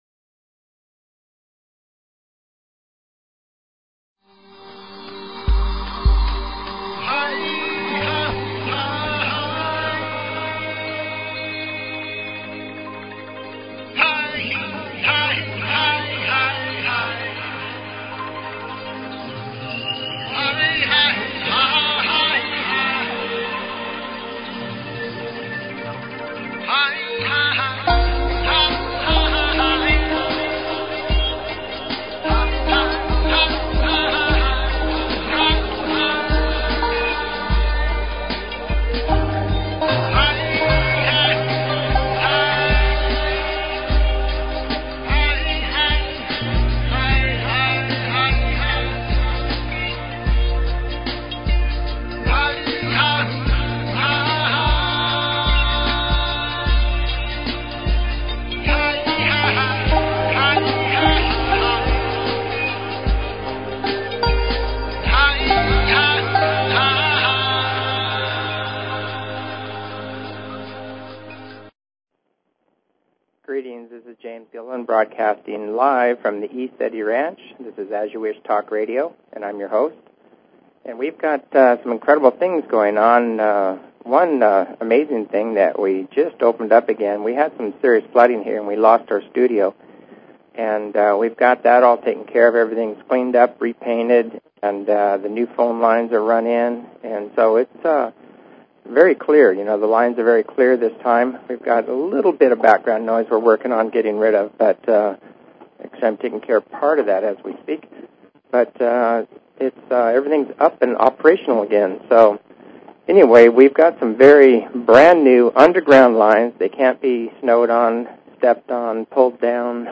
Talk Show Episode, Audio Podcast, As_You_Wish_Talk_Radio and Courtesy of BBS Radio on , show guests , about , categorized as
Back to the Field of Dreams, interviewing eyewitnesses concerning paranormal and ufo experiences at the ECETI Ranch
As you Wish Talk Radio, cutting edge authors, healers & scientists broadcasted Live from the ECETI ranch, an internationally known UFO & Paranormal hot spot.